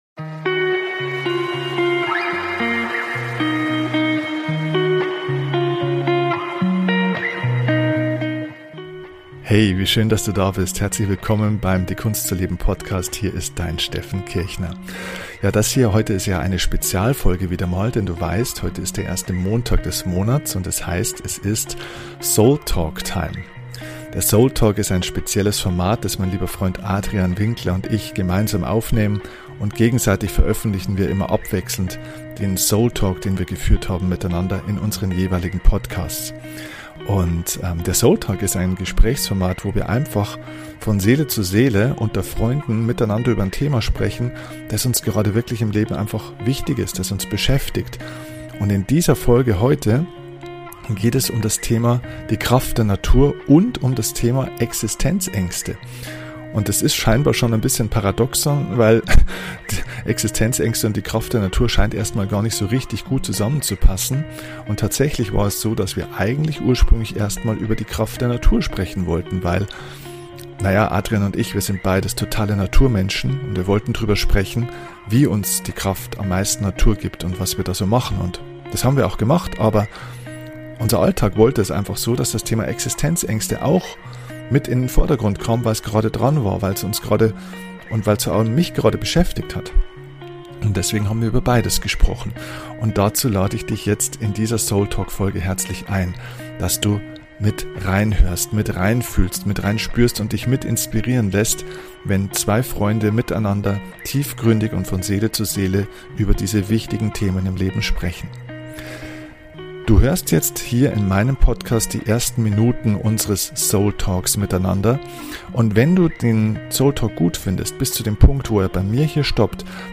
Heute geht es um zwei Themen, die erstmal überhaupt nicht zusammenpassen. Existenzängste und die Kraft der Natur. Lass Dich einfach treiben und hör bei unserem Gespräch ohne Skript zu.